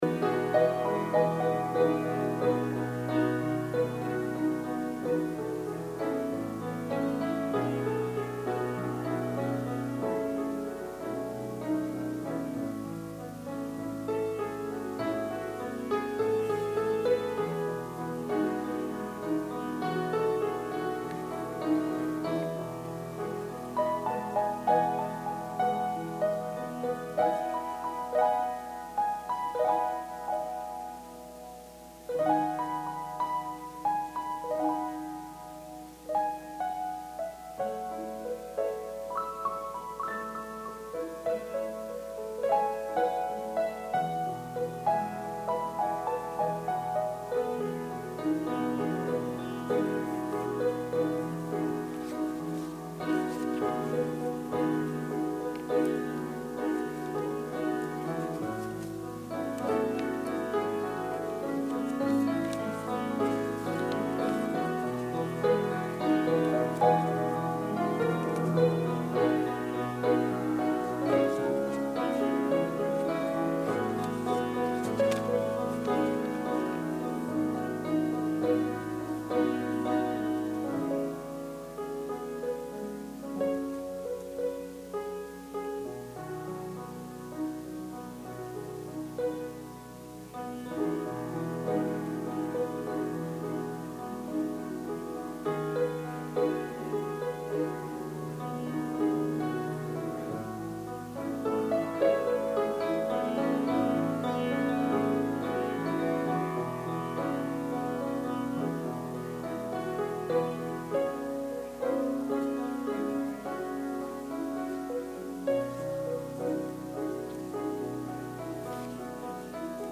Sermon – September 6, 2020
advent-sermon-sep-6-2020.mp3